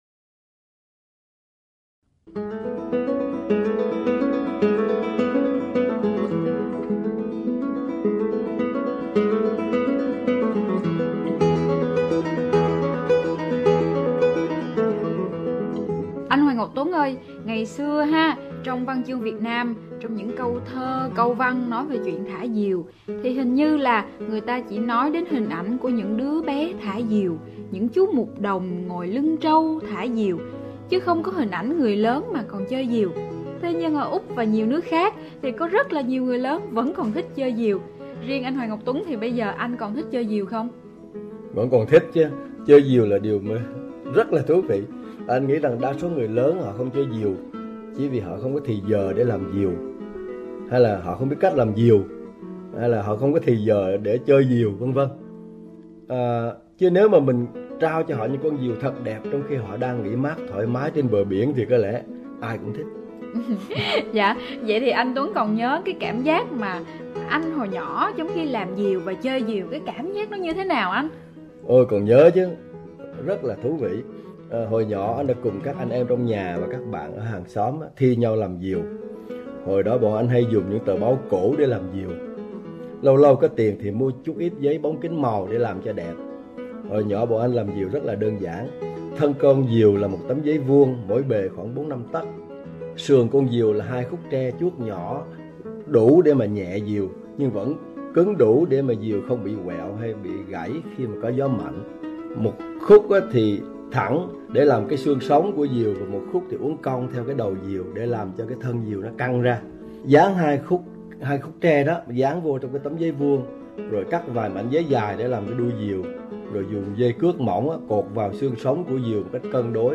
đã thực hiện những cuộc nói chuyện truyền thanh dài khoảng 15 phút vào mỗi đêm Chủ Nhật dưới hình thức phỏng vấn với nhà văn